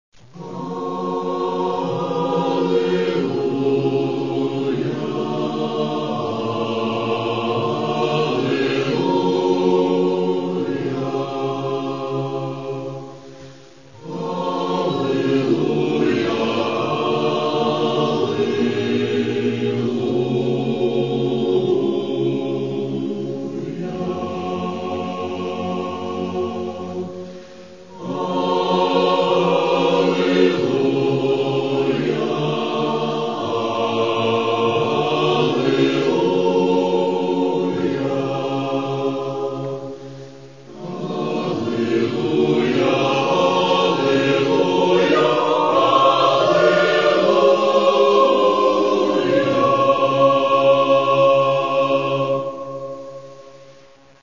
Літургійні твори